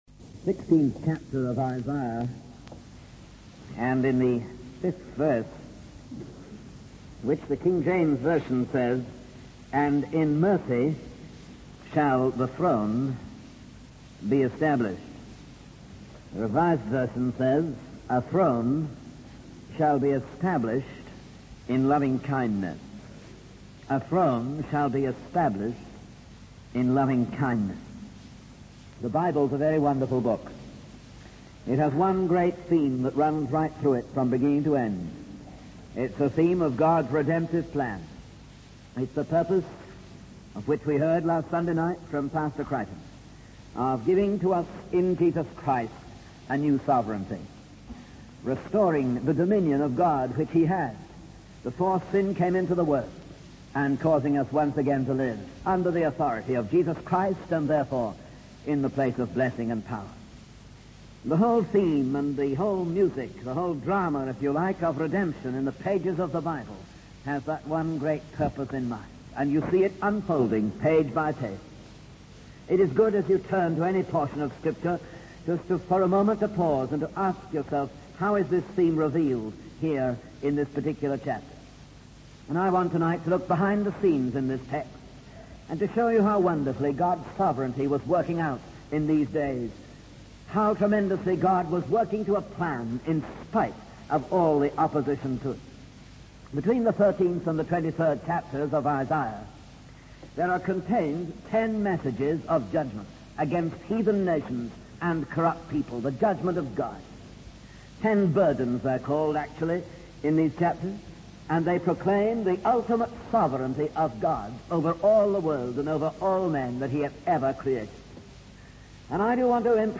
In this sermon, the preacher discusses the concept of God's judgment and the target of that judgment.